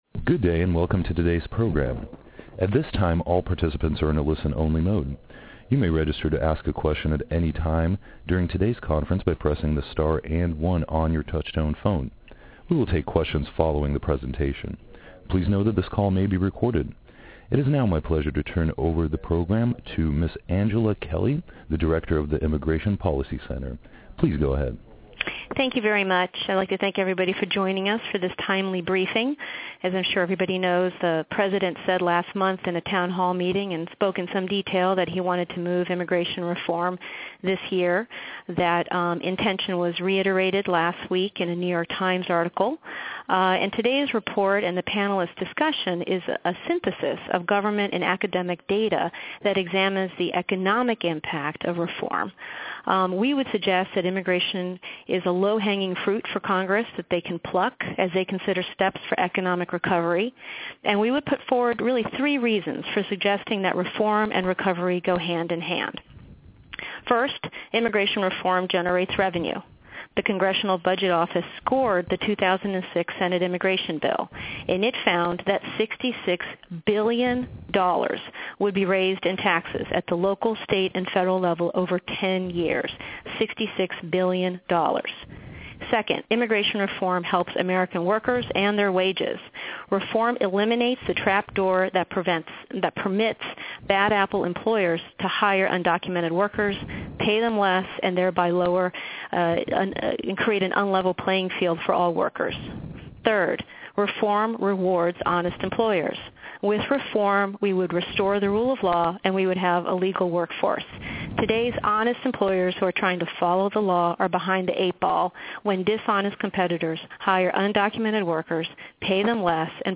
On-the-record, telephonic press briefing with Q&A to discuss an IPC summary of recent research on what legalizing undocumented immigrants would mean for the U.S. economy.